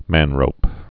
(mănrōp)